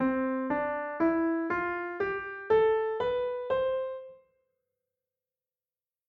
C major scale
C Major Scale
C-major-scale.mp3